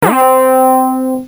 cartoon4.mp3